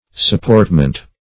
Supportment \Sup*port"ment\, n.